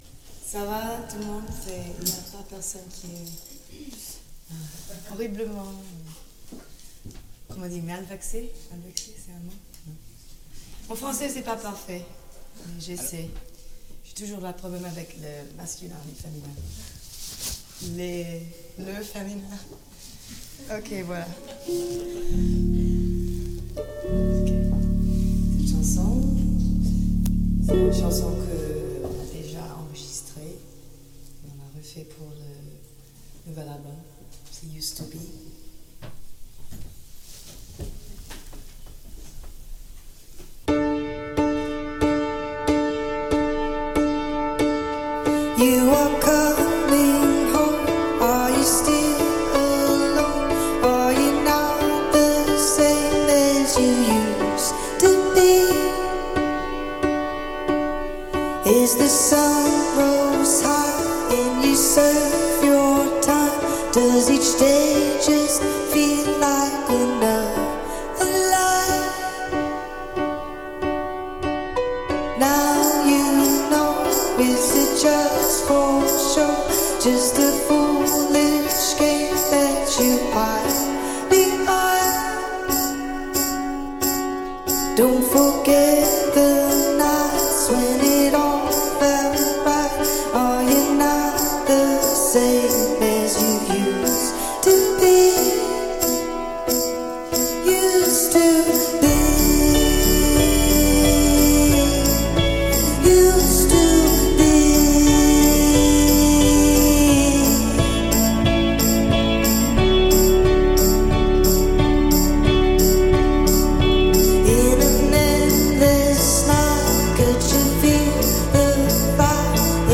Recorded in Paris
adding that dreamy luxuriance to the proceedings.